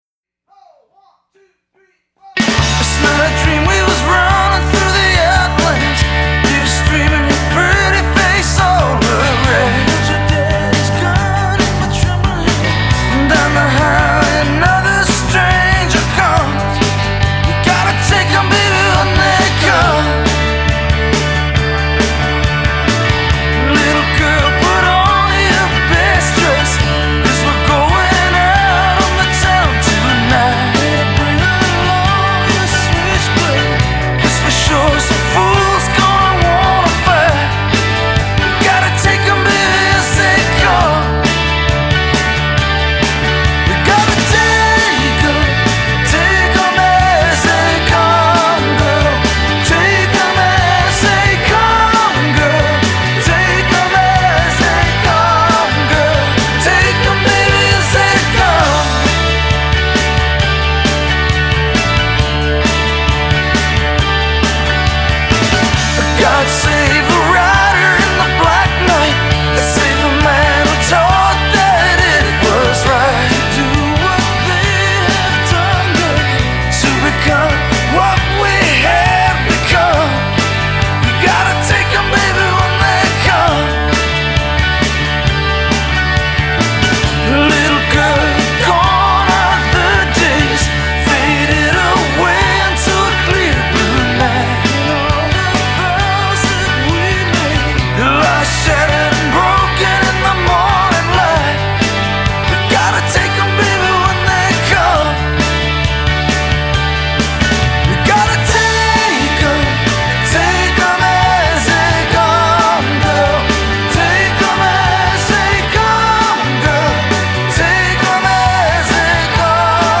La version studio
au studio Power Station (New York, NY)